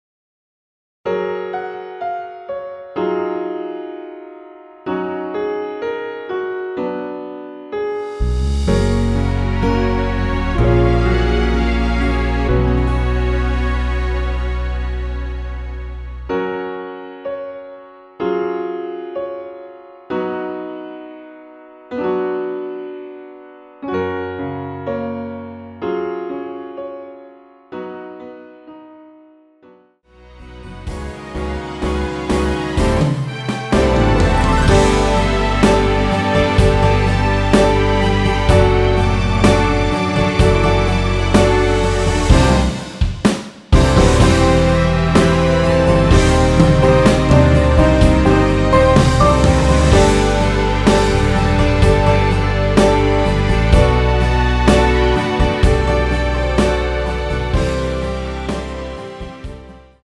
대부분의 남성분이 부르실수 있는 키로 제작 하였습니다.(미리듣기 참조)
F#
앞부분30초, 뒷부분30초씩 편집해서 올려 드리고 있습니다.
중간에 음이 끈어지고 다시 나오는 이유는